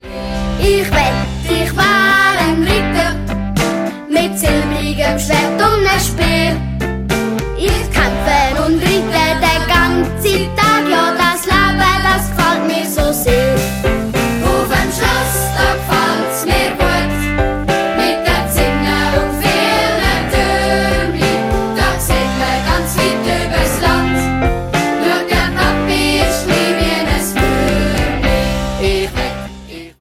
Schulmusical